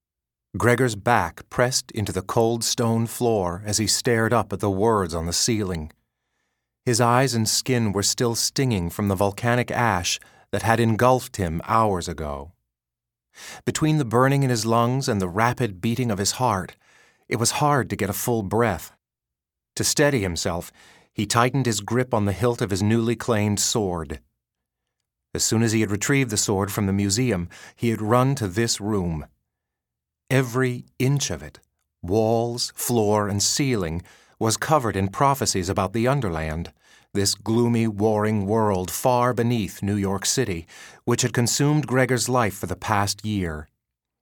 Code-of-Claw-Audio-Book-Sample.mp3